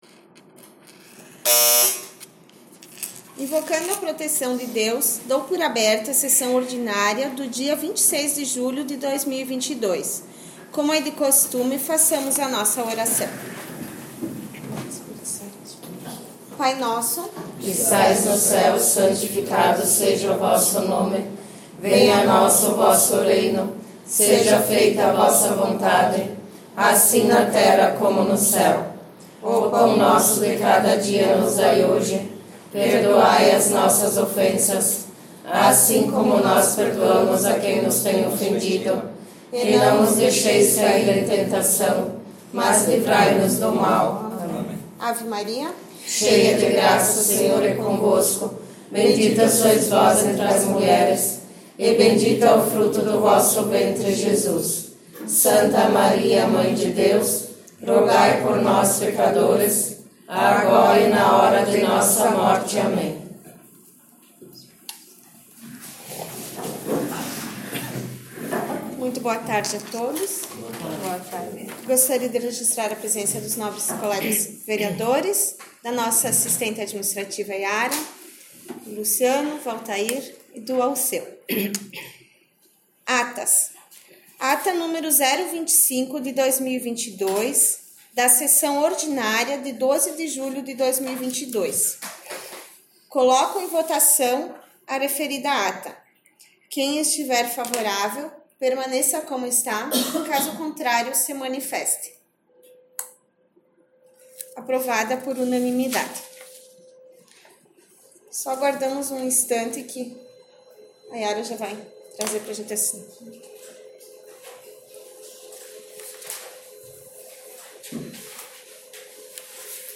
21 - Sessão Ordinária 26 julho 2022